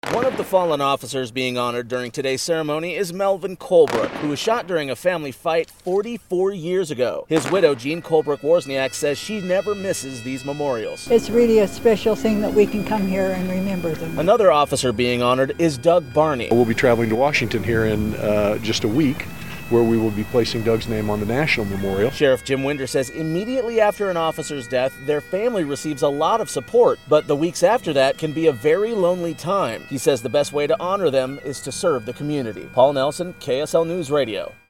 Somber moments and a 21 gun salute happened at the Salt Lake County Sheriff's Office, as the department honors officers that lost their lives.